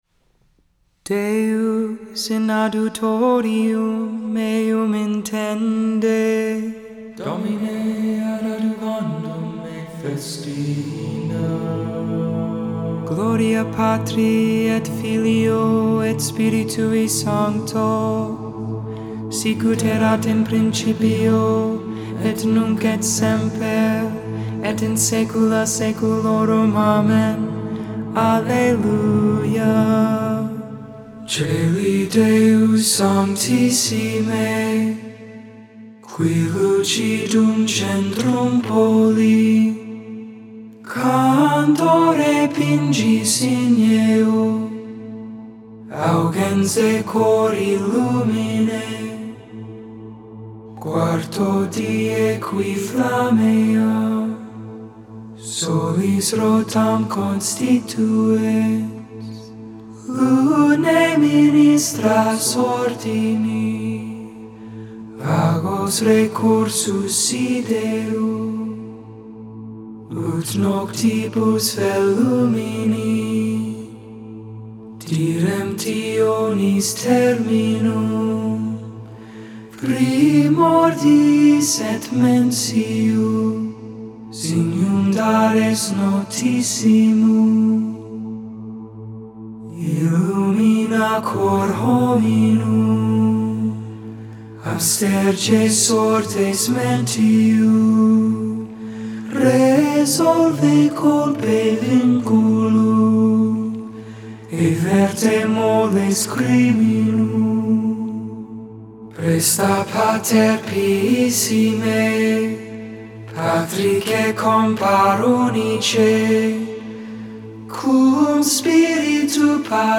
Vespers, Evening Prayer for the 5th Wednesday in Ordinary Time.